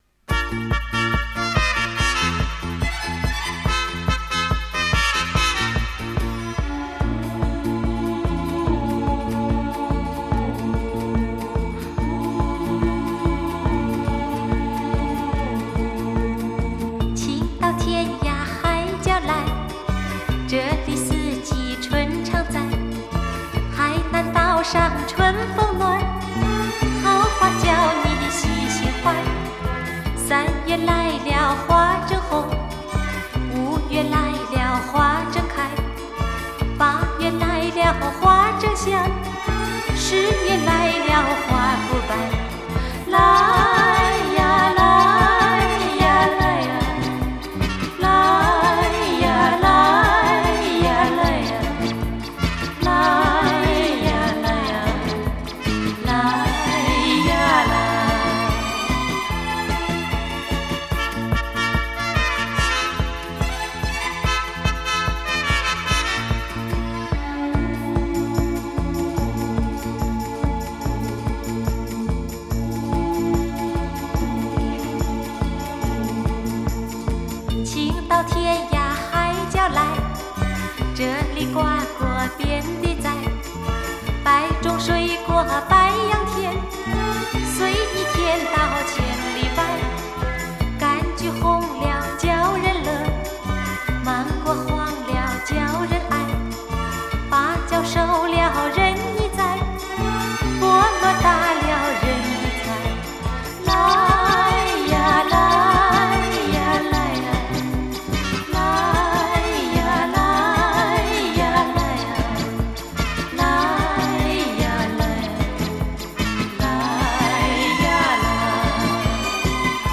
专辑风格：流行歌曲